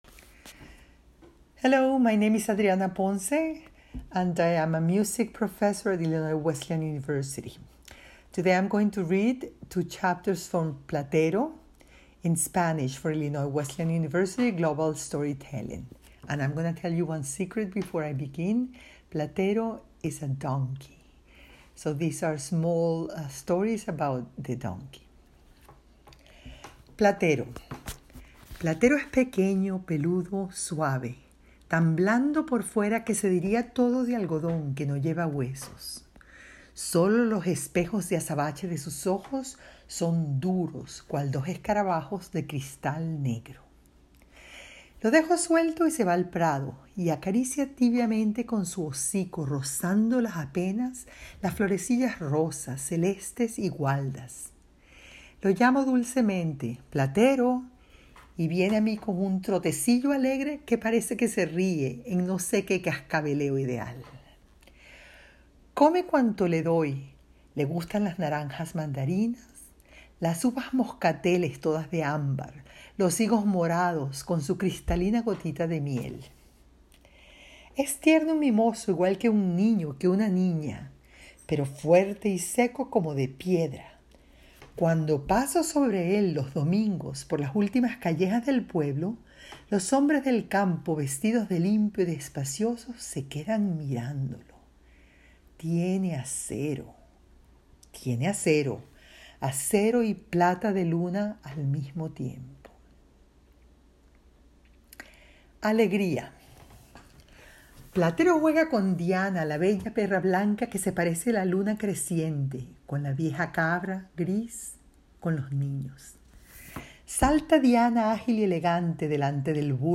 Spanish: Reading from Platero y yo- Platero and I